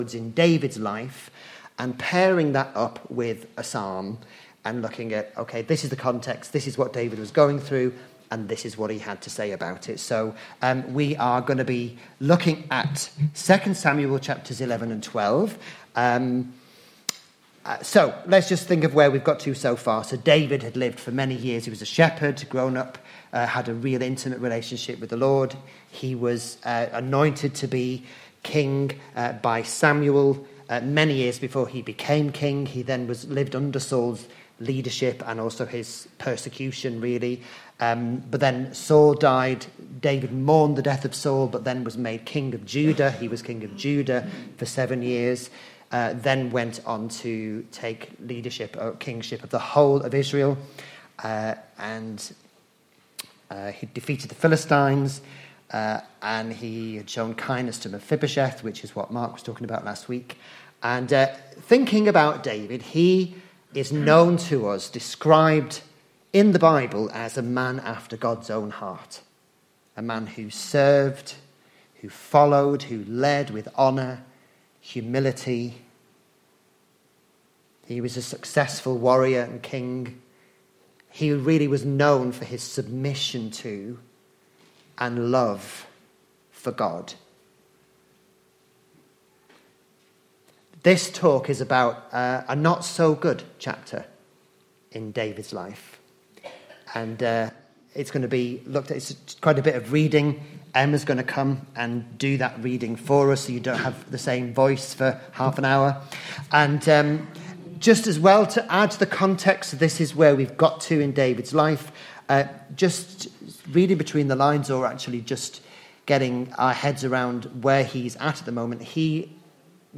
Morning Service: David - Grievous Sin - True repentance